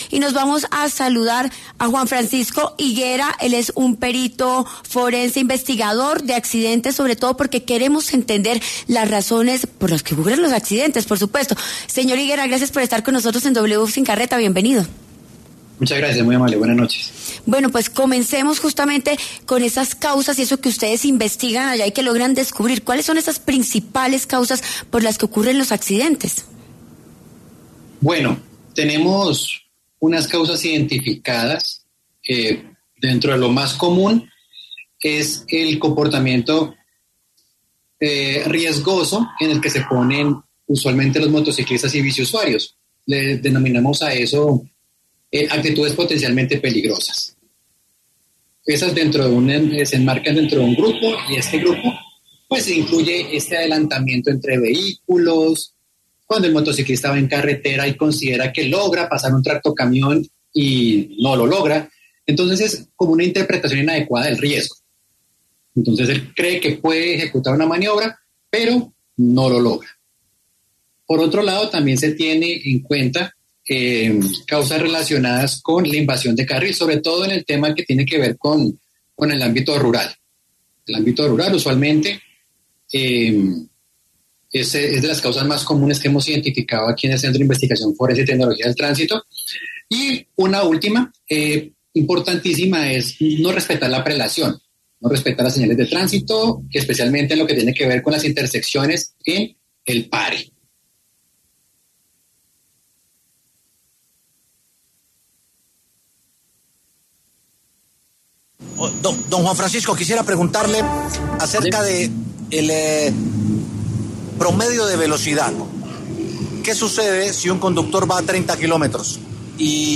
Experto habla sobre las causas más comunes de los accidentes de tránsito